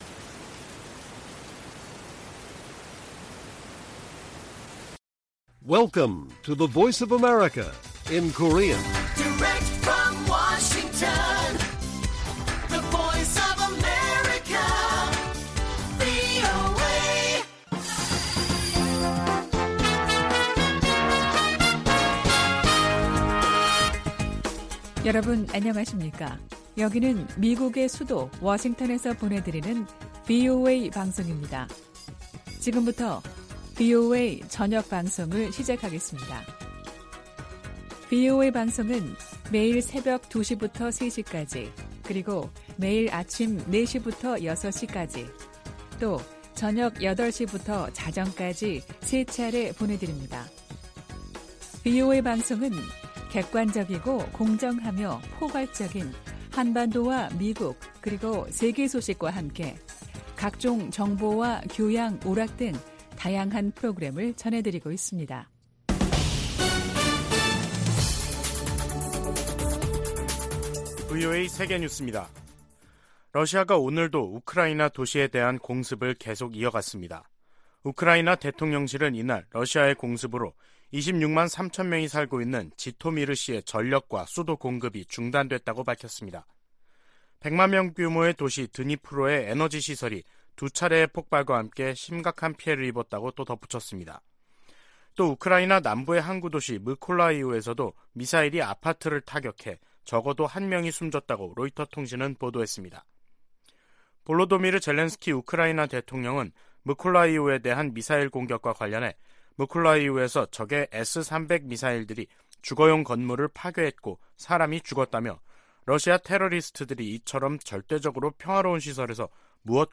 VOA 한국어 간판 뉴스 프로그램 '뉴스 투데이', 2022년 10월 18일 1부 방송입니다. 북한의 잇따른 도발은 무시당하지 않겠다는 의지와 강화된 미한일 안보 협력에 대한 반발에서 비롯됐다고 토니 블링컨 미 국무장관이 지적했습니다. 필립 골드버그 주한 미국대사는 전술핵 한반도 재배치론에 부정적 입장을 분명히 했습니다. 북한의 잇단 미사일 발사로 긴장이 고조되면서 일본 내 군사력 증강 여론이 강화되고 있다고 미국의 일본 전문가들이 지적했습니다.